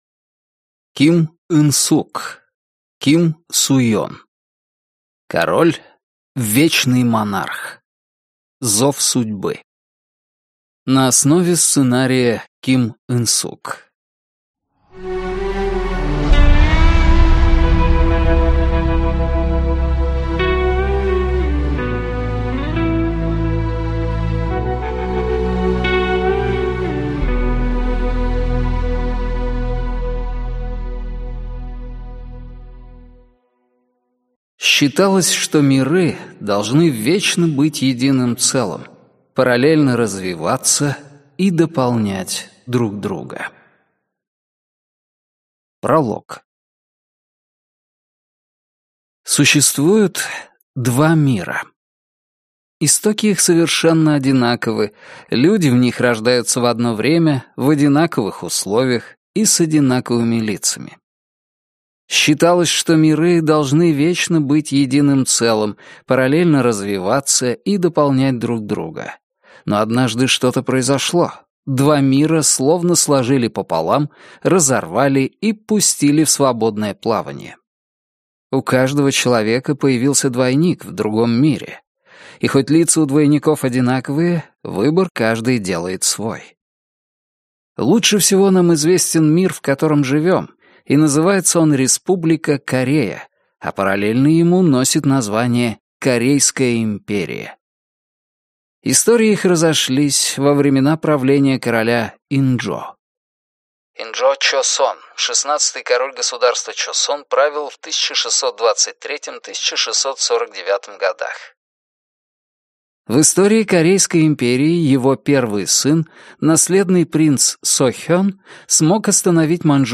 Аудиокнига Король: Вечный монарх. Зов судьбы | Библиотека аудиокниг